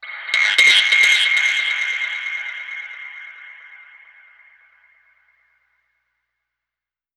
Index of /musicradar/dub-percussion-samples/134bpm
DPFX_PercHit_B_134-01.wav